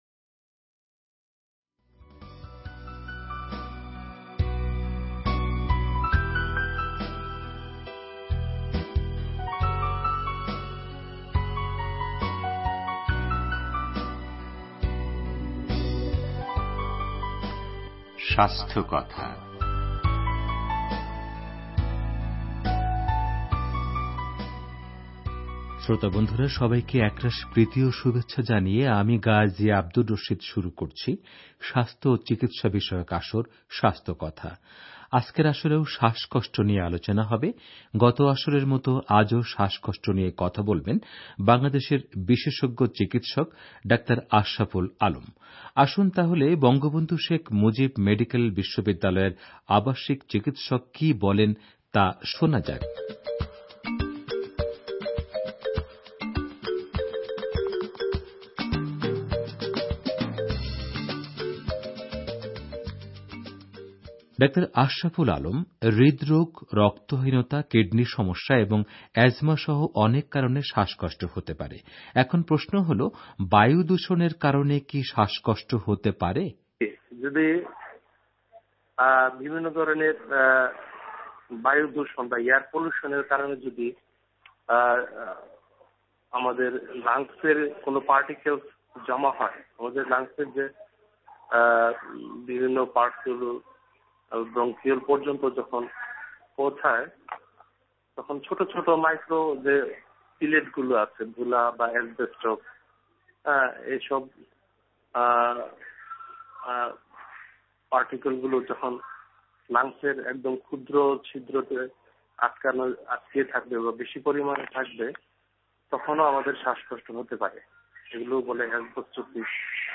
শ্বাসকষ্ট নিয়ে রেডিও তেহরানের স্বাস্থ্যকথা অনুষ্ঠানে আলোচনা করেছেন